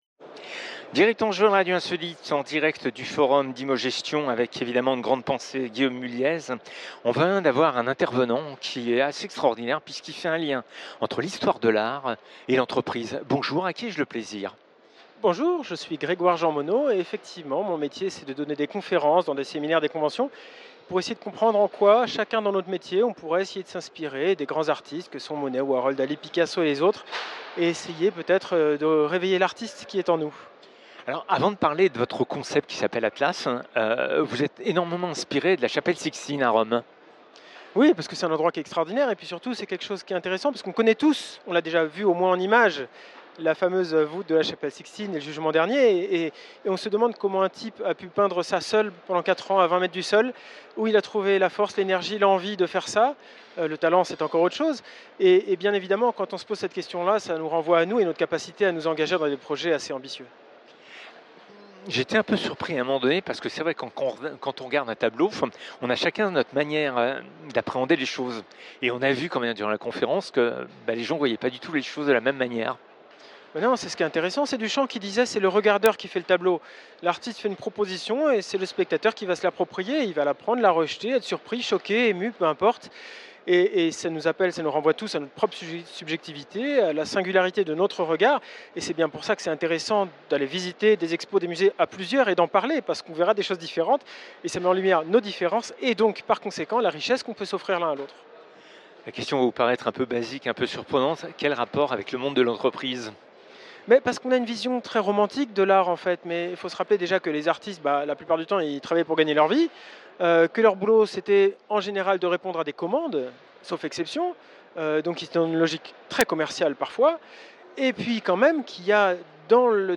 Le conférencier